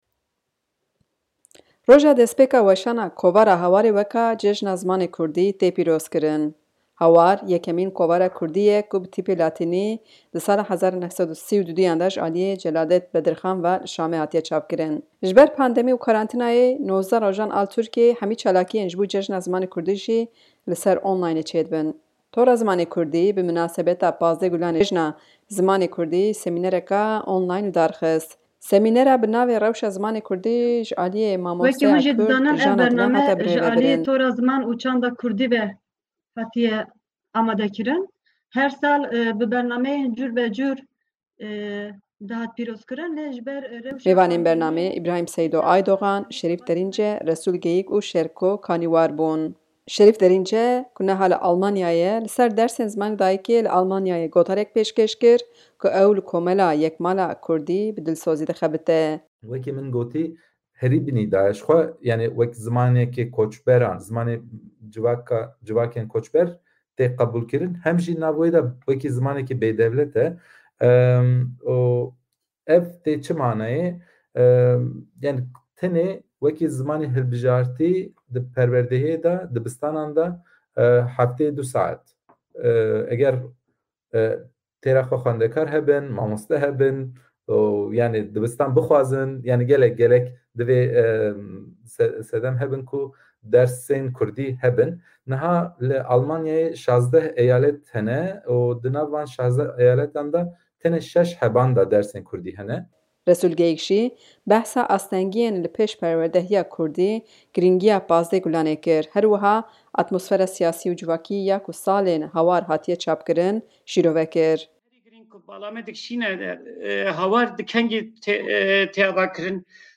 Tora Ziman û Çanda Kurdî li ser girîngîya vê rojê û rewşa Kurdî, bi beşdarîya lêkolînerên Kurd semînereke onlaynî li darxist.